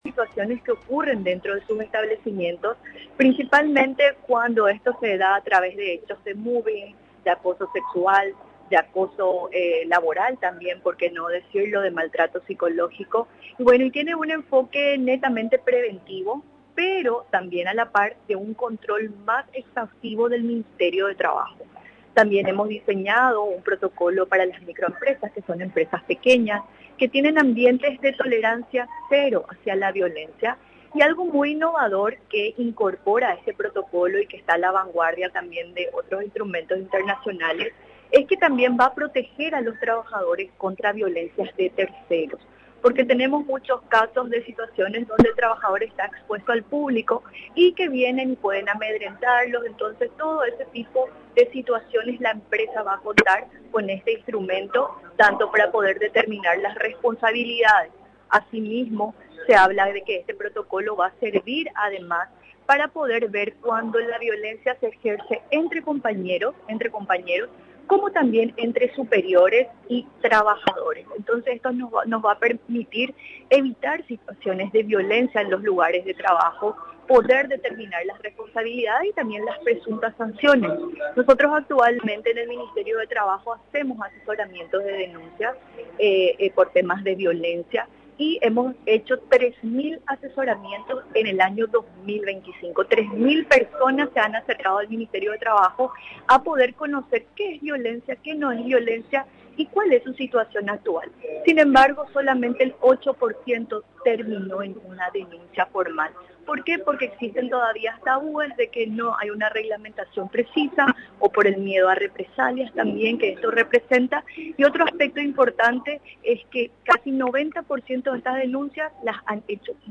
Durante la presentación, la ministra de Trabajo, Mónica Recalde, señaló que este protocolo representa un avance importante para fortalecer la protección de los trabajadores.